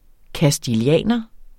Udtale [ kasdilˈjæˀnʌ ]